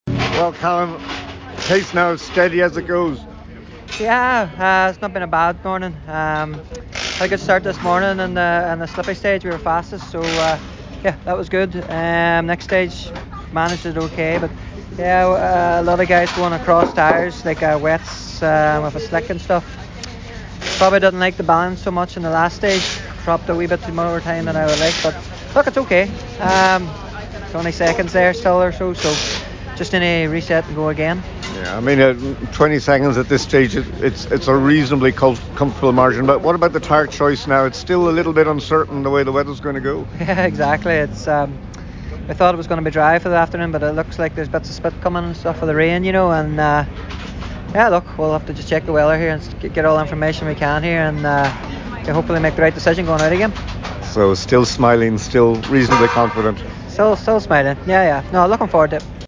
Rally Final Day: Service chats with the leaders